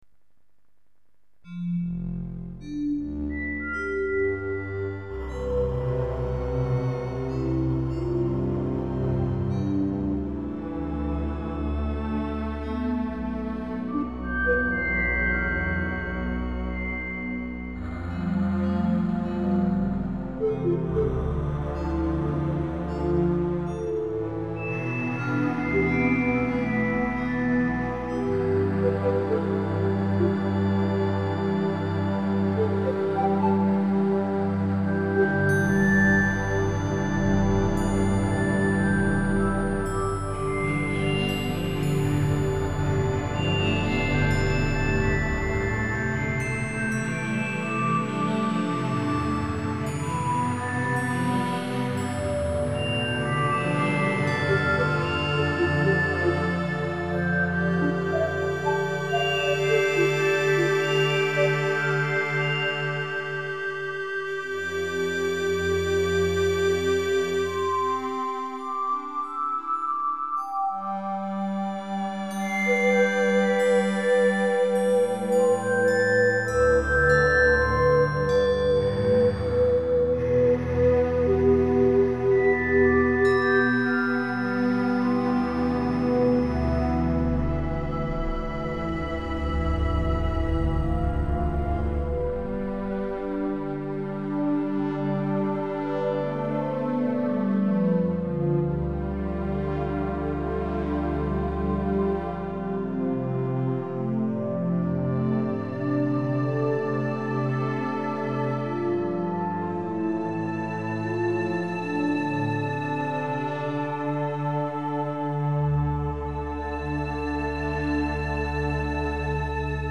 I do have a MIDI keyboard, and most of these pieces were produced from playing sessions on that keyboard, trying to respond to emotions and the build up of different sounds.
Early Comp - combination of 6 instrument tracks, recorded live from a MIDI keyboard, and played using a Yamaha TG-33 MIDI sound module.